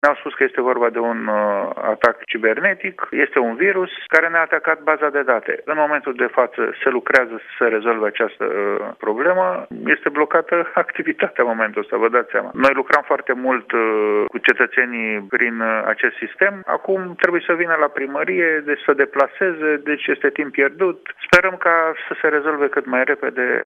De altfel, primarul Sectorului 1, Dan Tudorache a declarat la Europa FM că este vorba de un atac cibernetic.